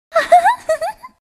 Princess Peach Giggles
princess-peach-giggles.mp3